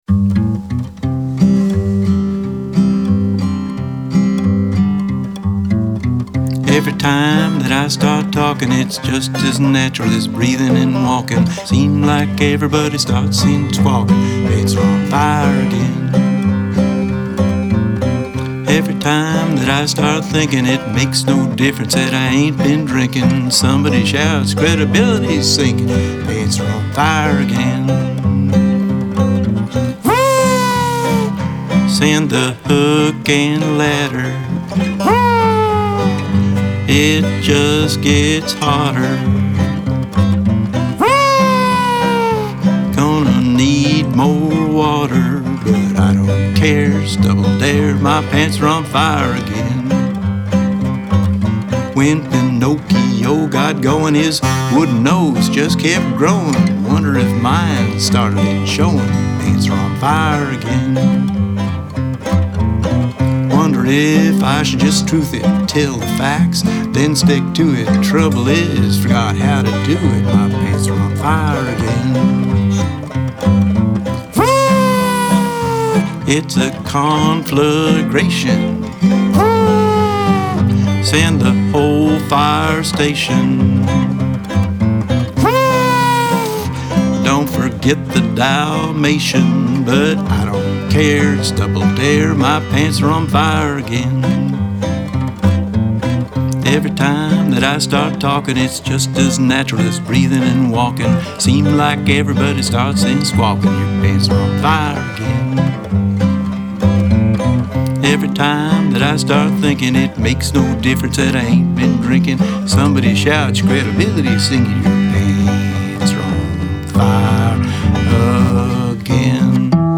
Singer/Songwriter, Political Activist